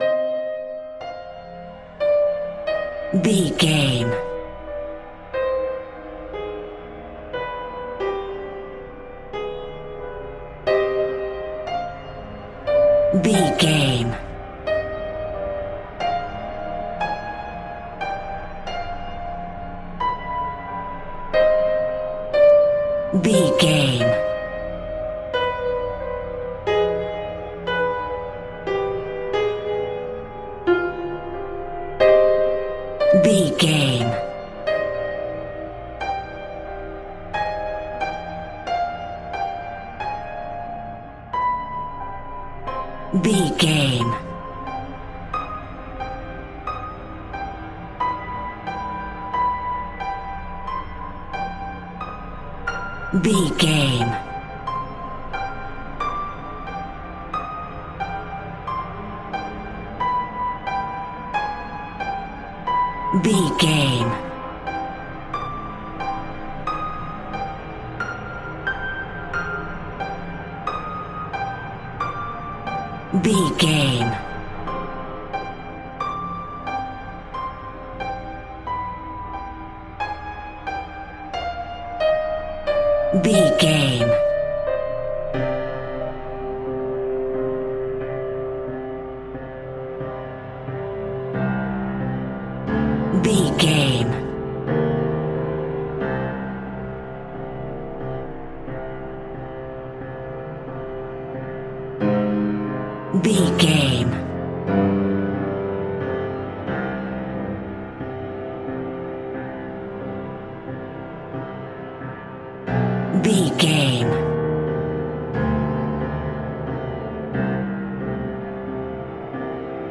In-crescendo
Thriller
Aeolian/Minor
scary
tension
ominous
suspense
haunting
eerie
percussion
horror
synths
atmospheres